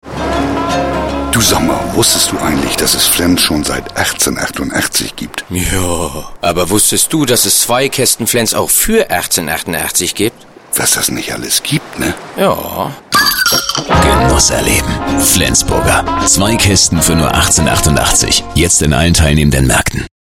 Schauspieler -Sprecher-Autor
norddeutsch
Sprechprobe: Industrie (Muttersprache):
voice over german